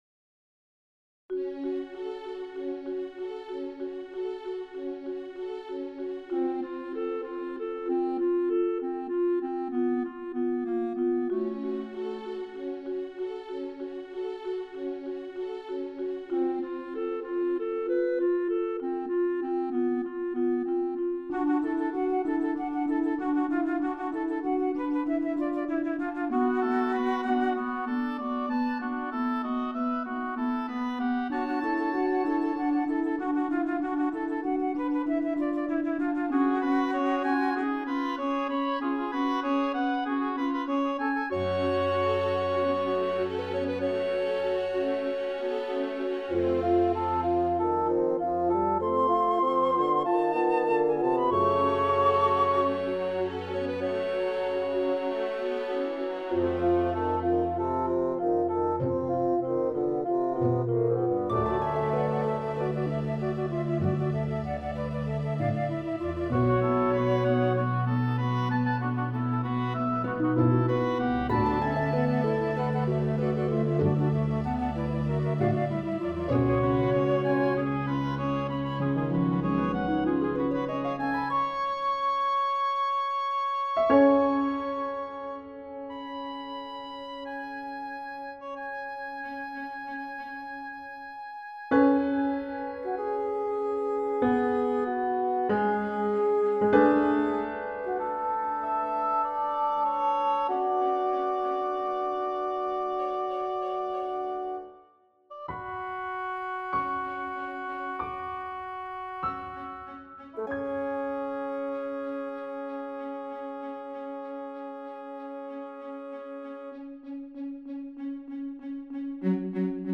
Instr: 2/2/2/2; 2 hns, 2 tpts; perc; harp; strings; piano solo